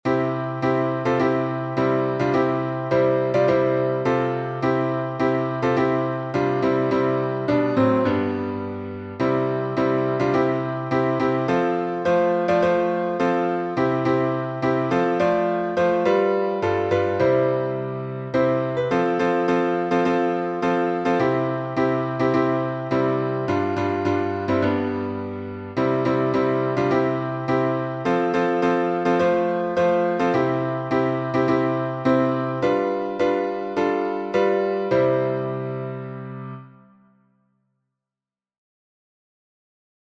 Stepping in the Light — C major.